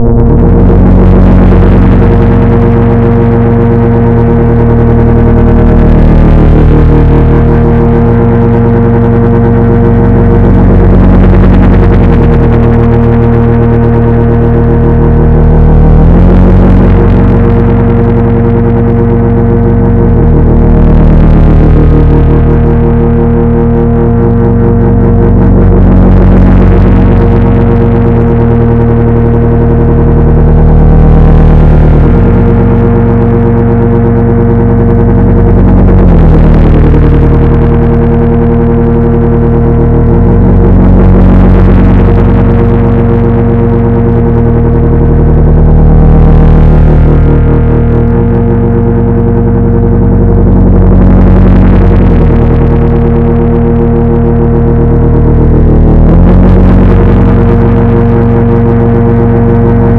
Pleased with this test drone I made for a gestku, but it benefits from being more than 11 seconds.